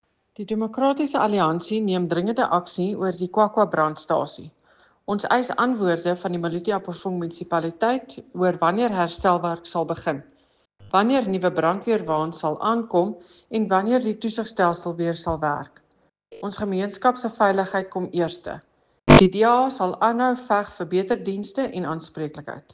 Afrikaans soundbite by Cllr Eleanor Quinta.